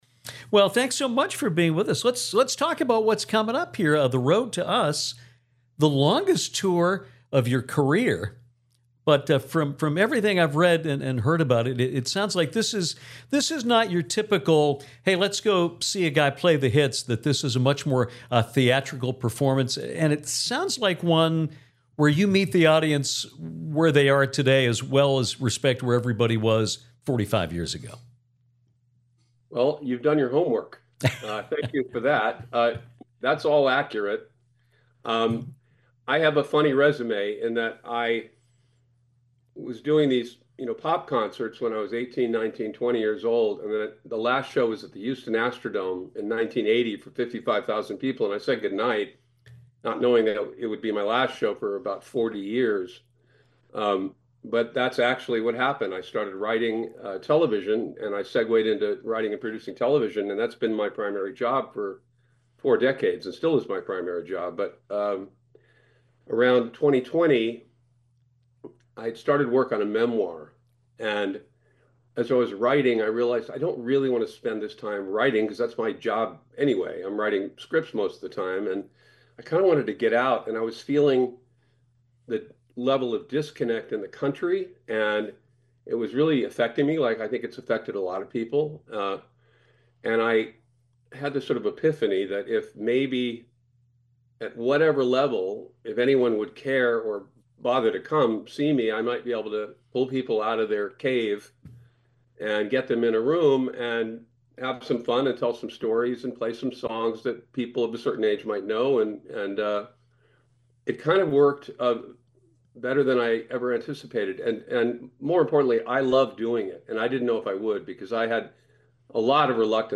Terrific interview with Shaun.